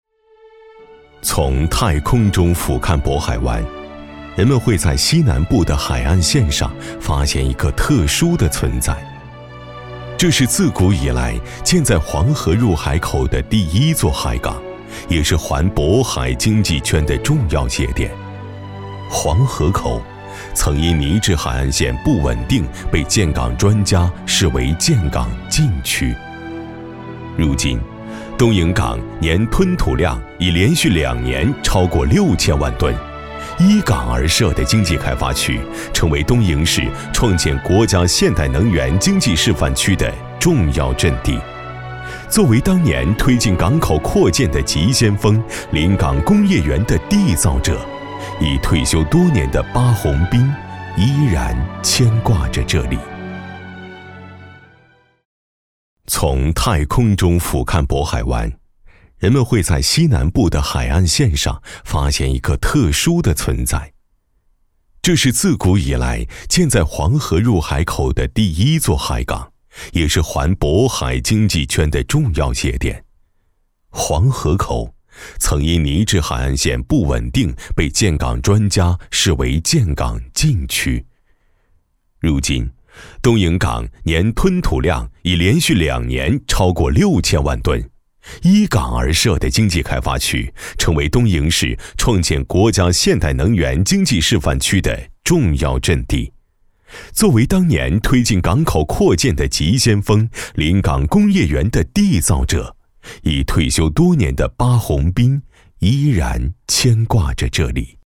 男173-【纪录片】武汉春节 样音